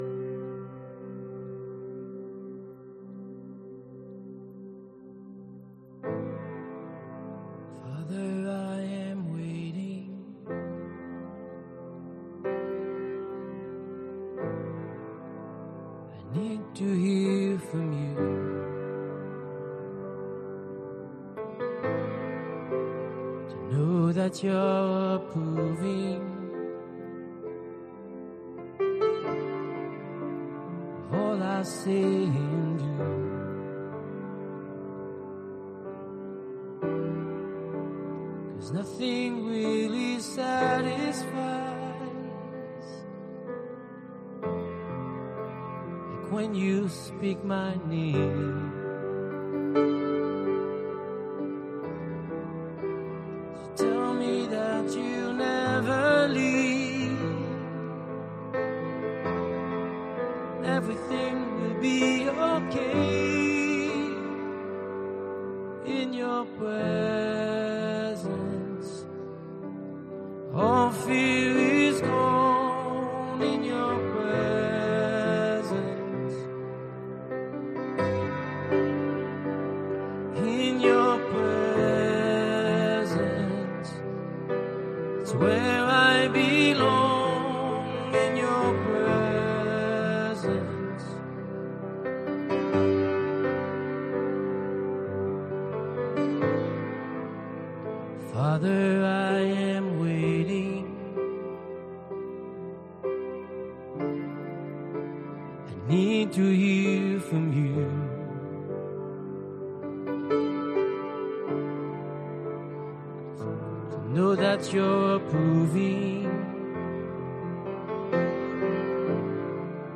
Soaking Prayer and Worship (audio only)